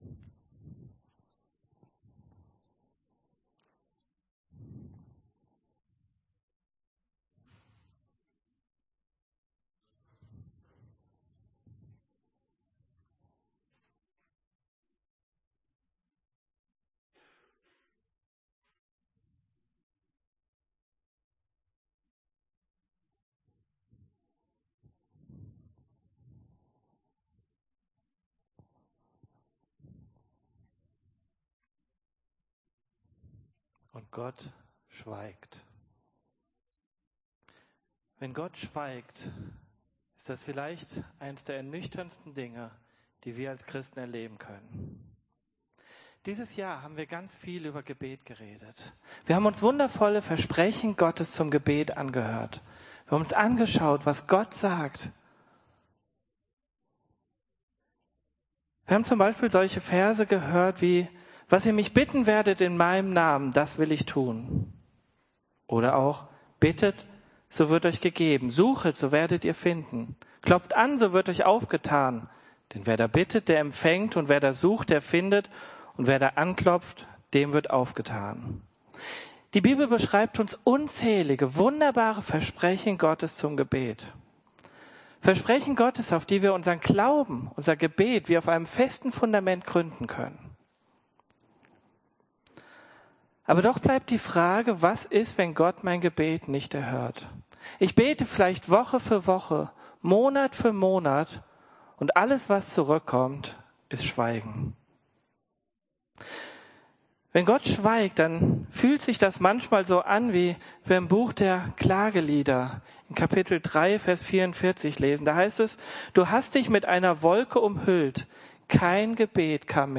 „Unerfüllte Gebete“ – Predigtgliederung – Freude an Gott
Predigt-16-Unerfuellte-Gebete.mp3